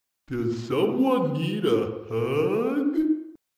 На этой странице собрана коллекция звуков и голосовых фраз Haggy Wagy.
Голос Хаги Ваги